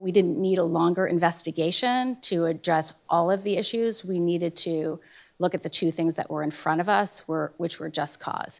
Norris says the board moved swiftly to accept Roberts’ resignation because the district got confirmation from federal officials that Roberts is not eligible to work in this country and state officials revoked his license to be an administrator.